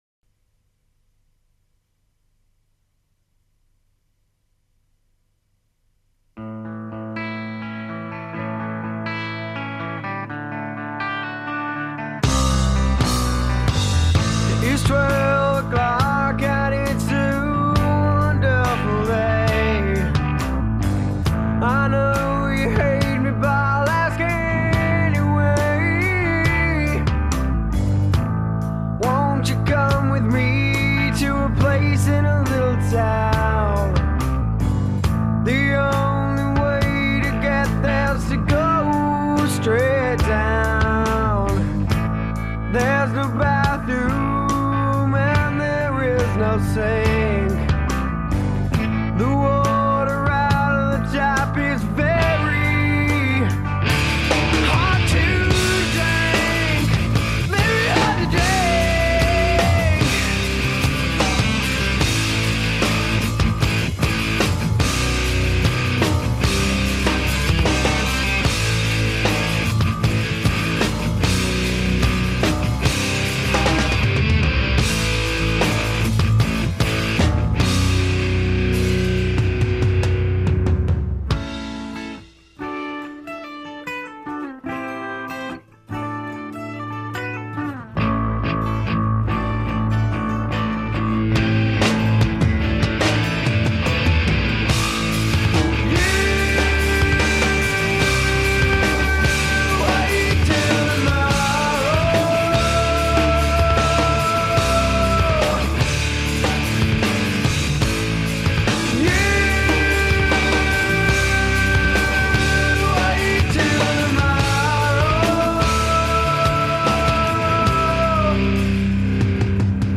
Grunge Rock